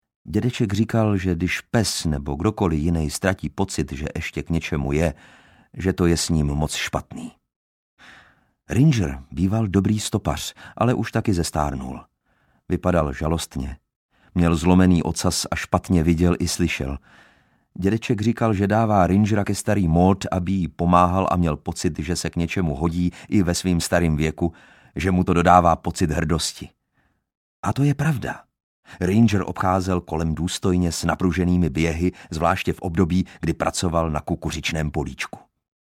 Škola Malého stromu audiokniha
Ukázka z knihy